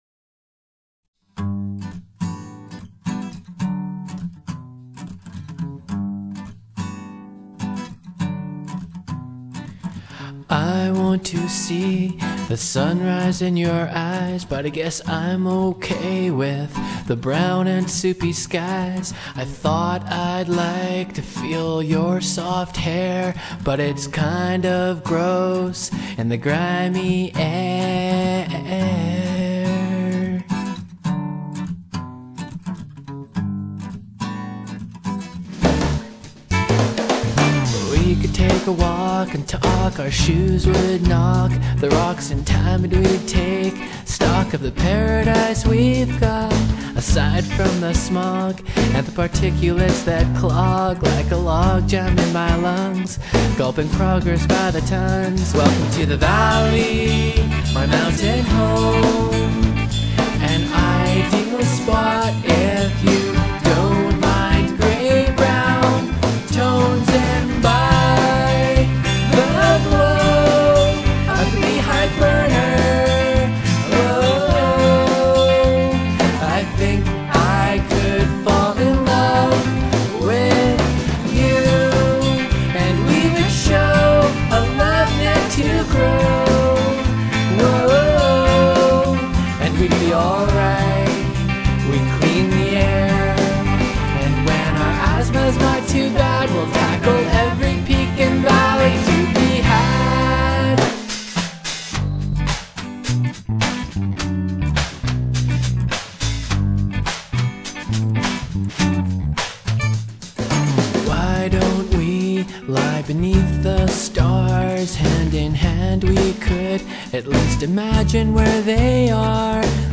Guitar, Vocals
Harmony
Percussion, Bass